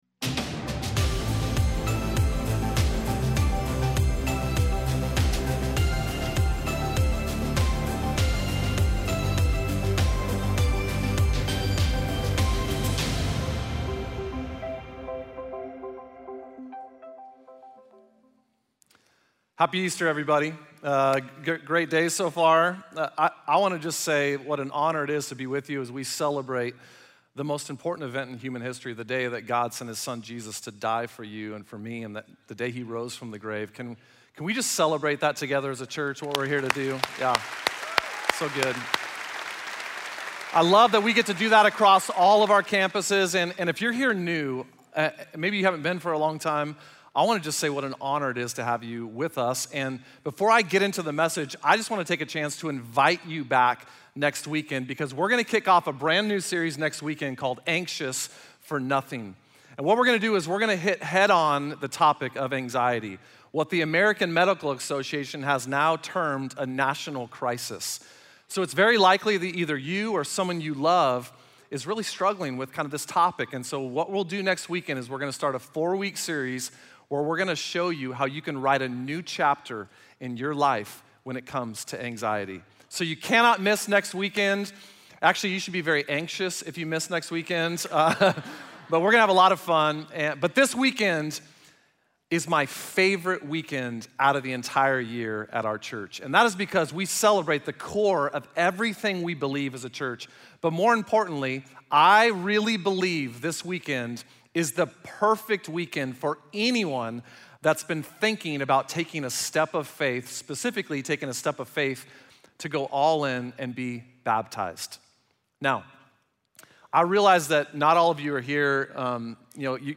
Message Only